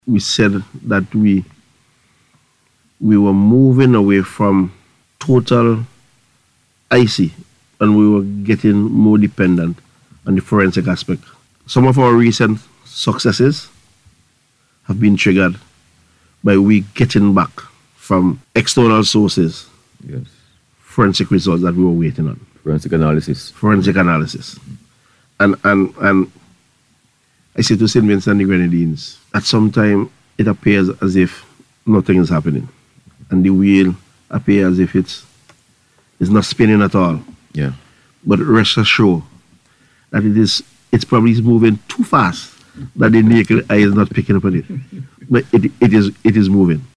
Assistant Commissioner of Police in Charge of Crime Trevor Bailey speaking on the Police on the Beat Programme said one such factor is the regular review of unsolved cases.